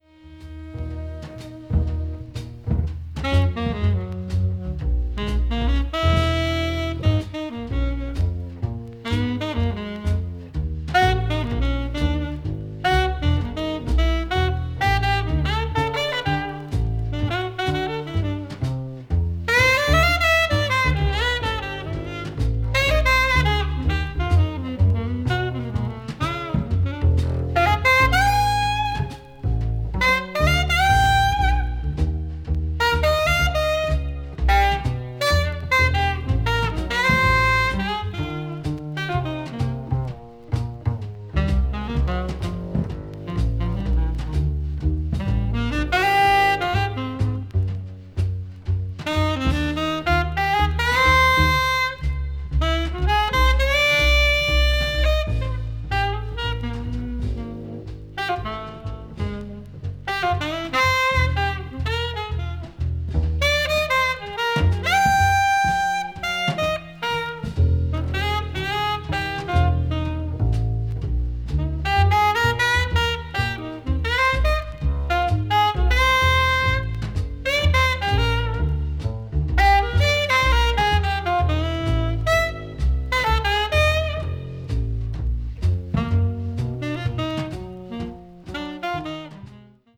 avant-jazz   free improvisation   free jazz   post bop